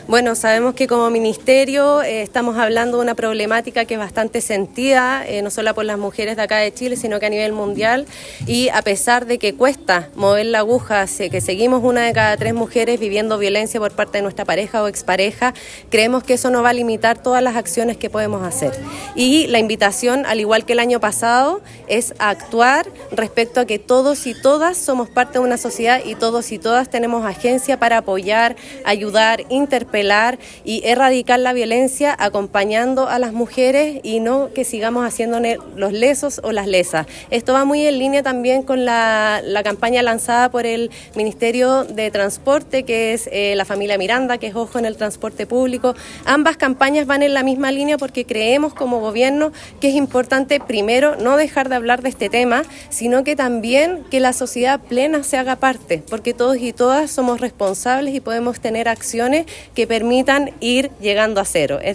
La actividad se realizó en la Sala de Sesiones del Municipio y contó con la participación de autoridades regionales, provinciales y locales; además de dirigentas sociales.
La Seremi de la Mujer y Equidad de Género, Macarena Gré, señaló que esta es una campaña que buscar sensibilizar y actuar frente a esta problemática social, para avanzar en la erradicación de la violencia contra las mujeres.